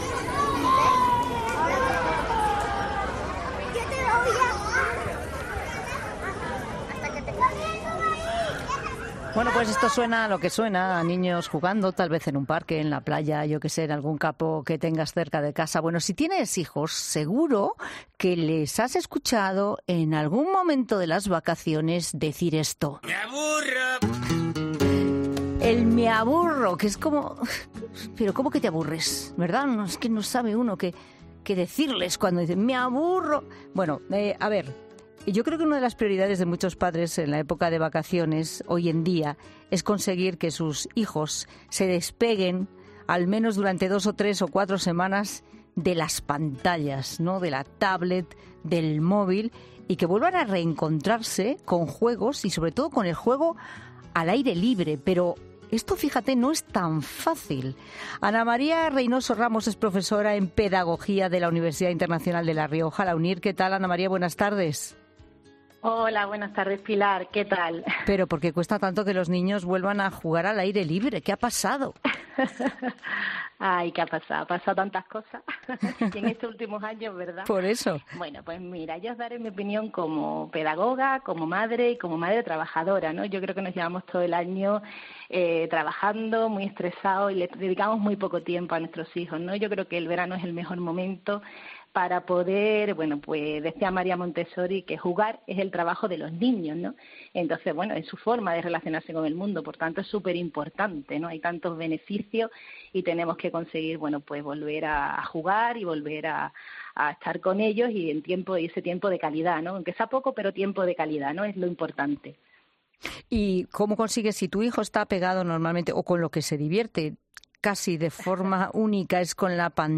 Una psicopedagoga explica en 'La Tarde' de COPE cómo alejar a los niños de las pantallas en verano